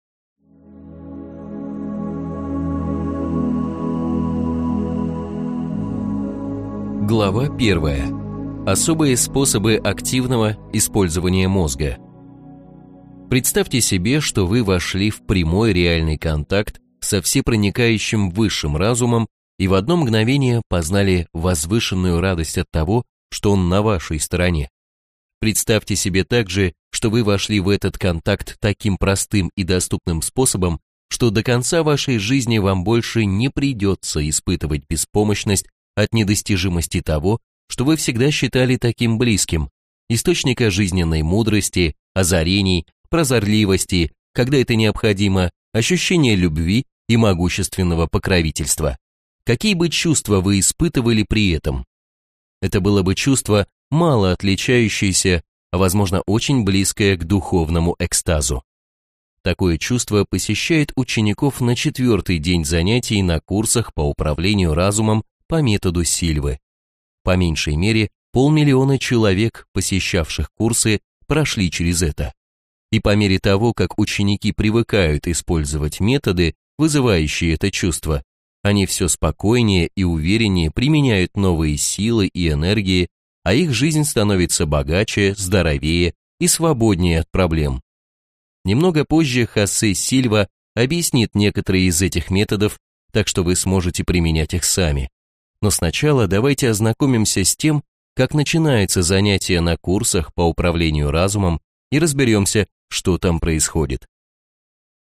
Аудиокнига Метод Сильвы. Управление разумом | Библиотека аудиокниг